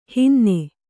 ♪ hinne